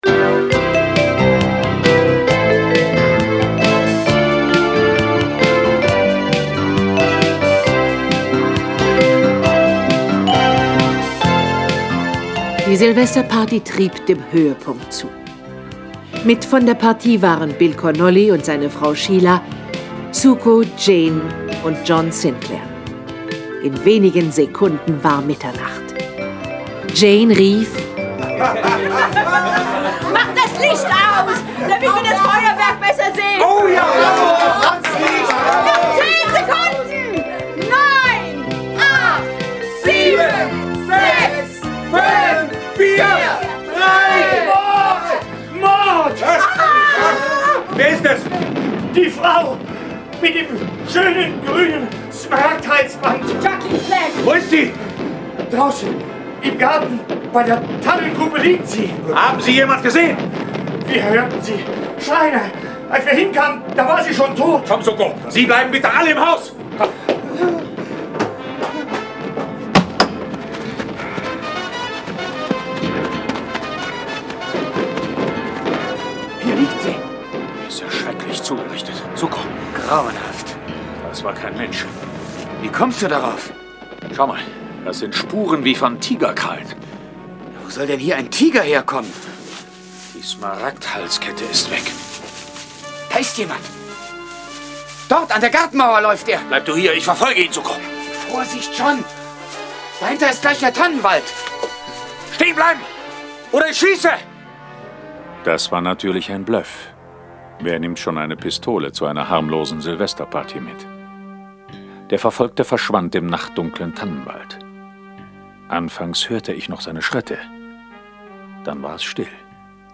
John Sinclair Tonstudio Braun - Folge 93 Im Landhaus der Schrecken. Jason Dark (Autor) diverse (Sprecher) Audio-CD 2019 | 1.